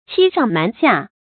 欺上瞞下 注音： ㄑㄧ ㄕㄤˋ ㄇㄢˊ ㄒㄧㄚˋ 讀音讀法： 意思解釋： 對上欺騙，博取信任；對下隱瞞，掩蓋真相。